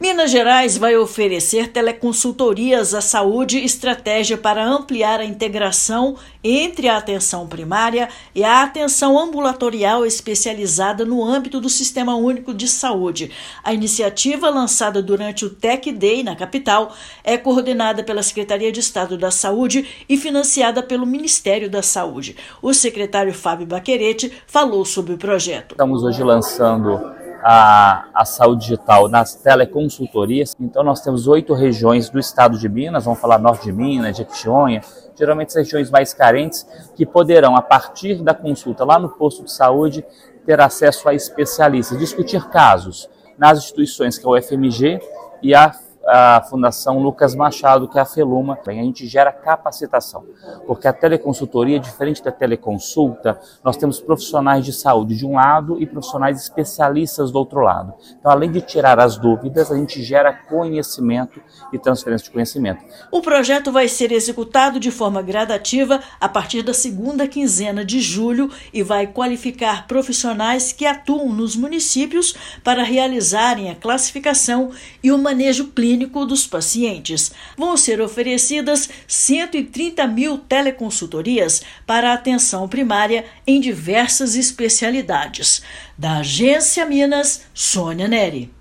Inovação lançada pelo Governo de Minas vai qualificar profissionais que atuam nos municípios para realizar a classificação e atendimento de forma mais assertiva aos pacientes. Ouça matéria de rádio.